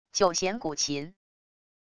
九弦古琴wav音频